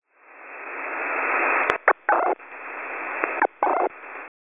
26148.6kHz on 03-22-26 at 10:06 PM Eastern, what is the signal type?